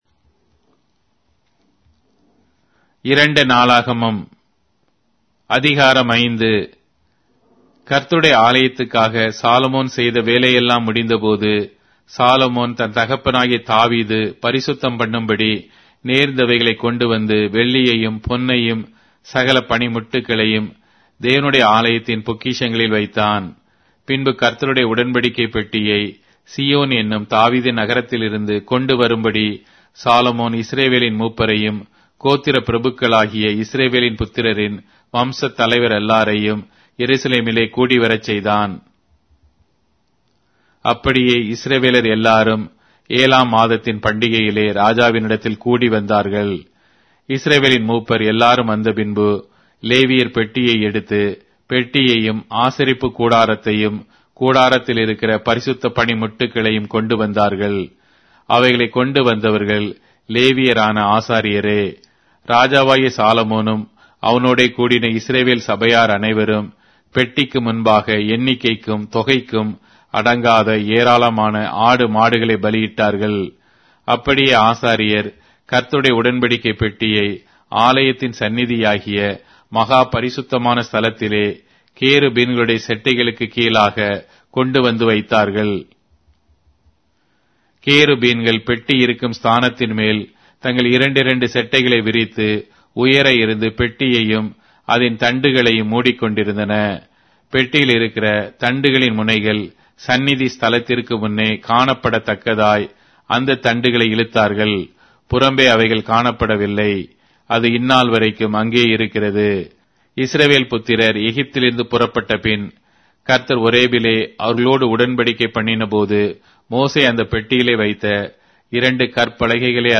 Tamil Audio Bible - 2-Chronicles 3 in Rv bible version